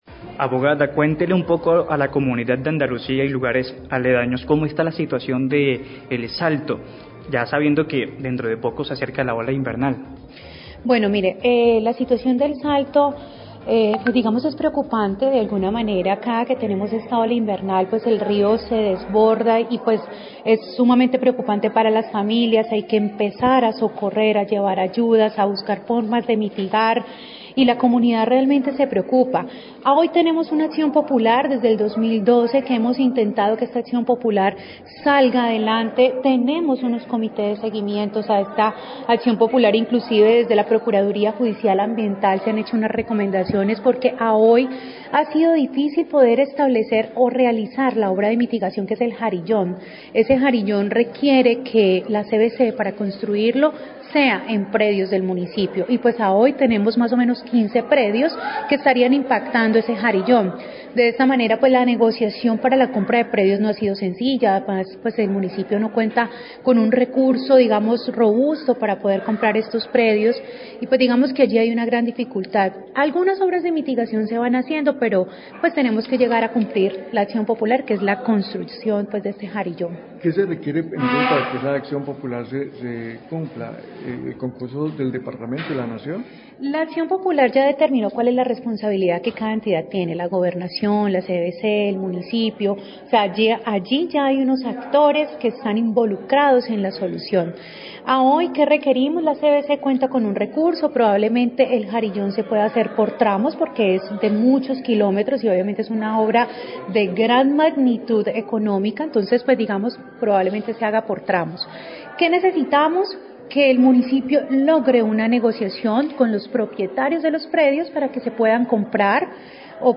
Radio
La Personera de Tuluá responde a pregunta sobre al situación del cgto del Salto por inundaciones debido a las lluvias. Agrega que la CVC no ha podido iniciar con la construcción de un jarillón que mitigue el daño porque la alcaldía no tiene recursos para la compra de un predio.